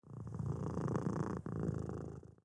Hub Cat Sound Effect
hub-cat.mp3